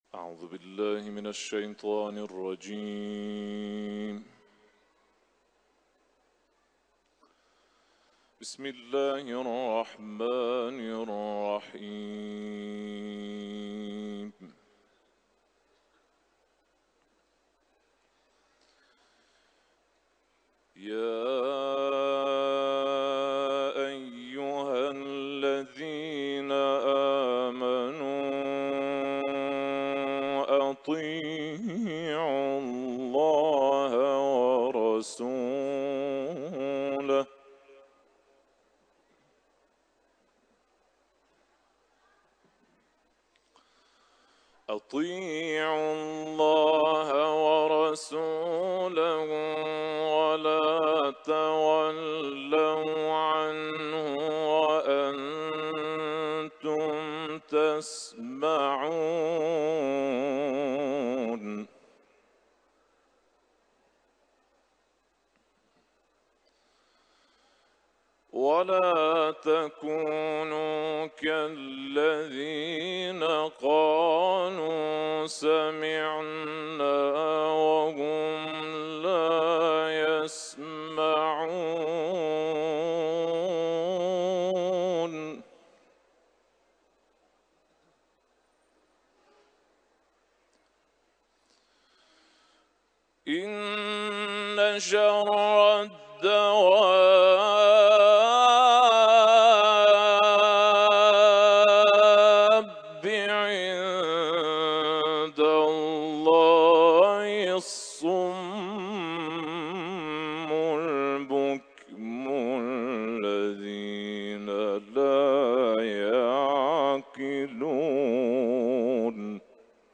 Etiketler: tilavet ، İranlı kâri ، Enfal suresi ، Alak sûresi